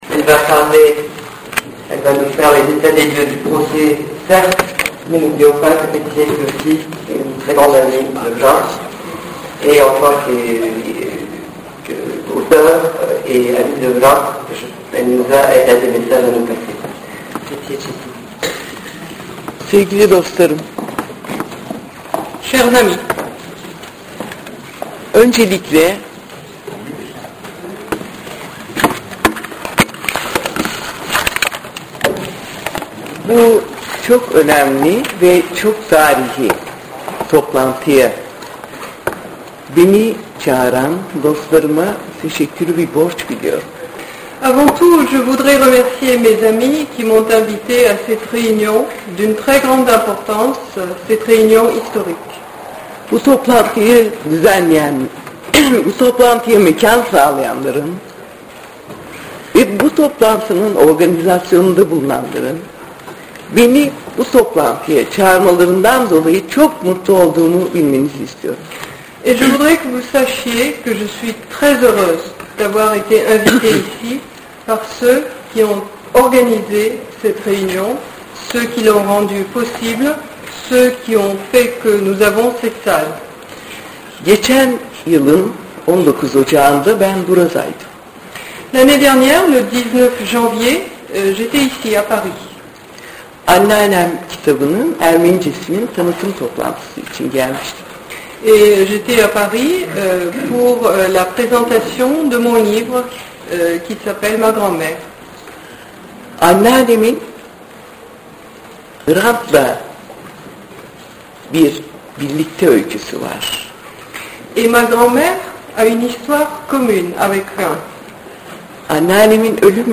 Il s’agit ici de la version intégrale sous forme de document audio dont vous voudrez bien excuser la qualité médiocre, puisque que le document reste malgré tout très audible et c’est bien là l’essentiel.